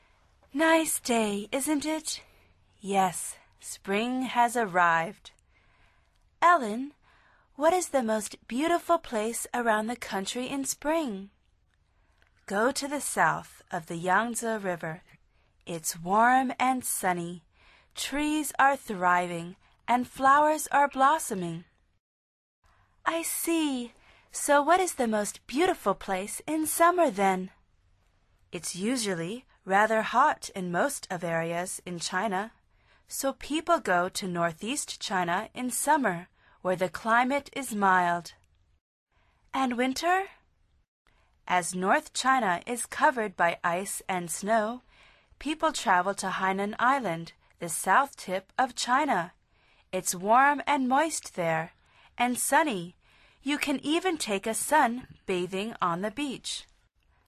Curso Intermedio de Conversación en Inglés
Al final repite el diálogo en voz alta tratando de imitar la entonación de los locutores.